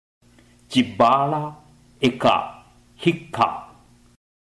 唱誦